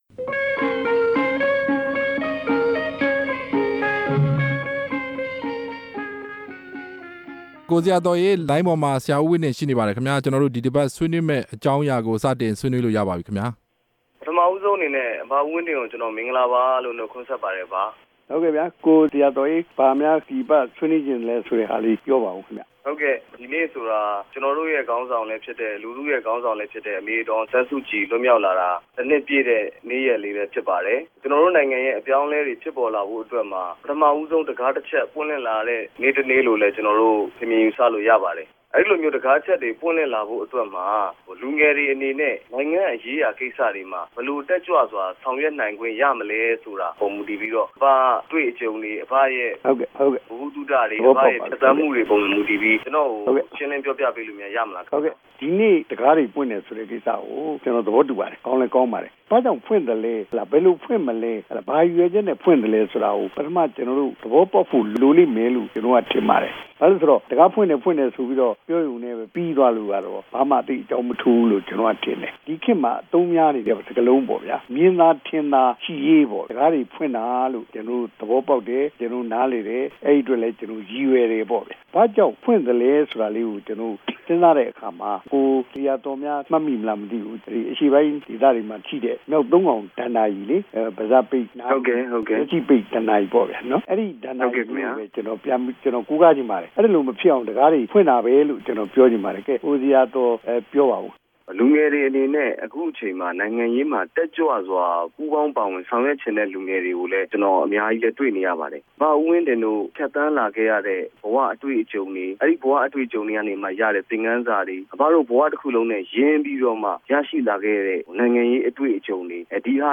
ဦးဝင်းတင်နှင့် စကားပြောခြင်း